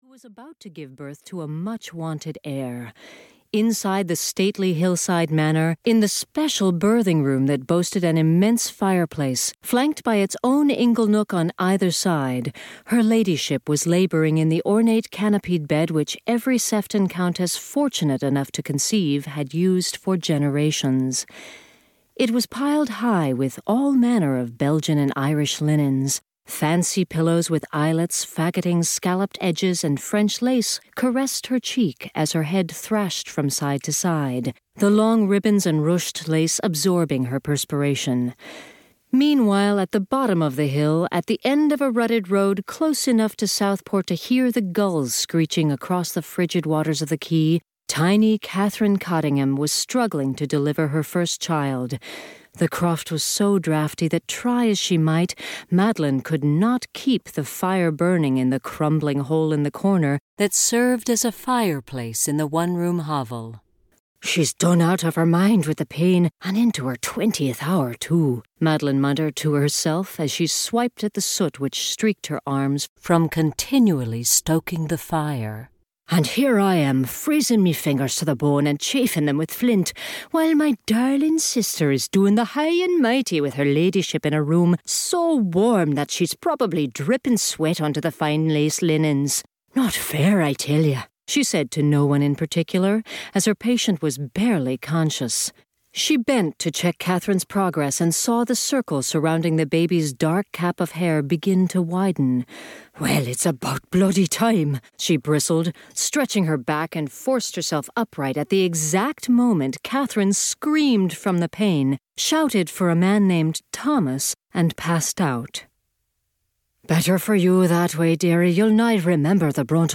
The Earl's Wet Nurse (EN) audiokniha
Ukázka z knihy